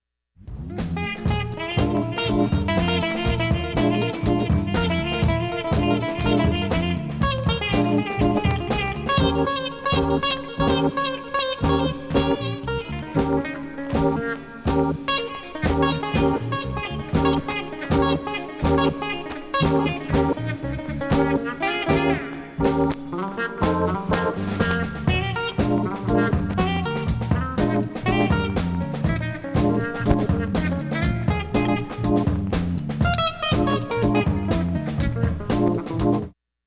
A-1後半に針飛び補修あり
軽いプツ跡ののち１回ポツ音が出ます、
３０秒の箇所でポツ出る箇所が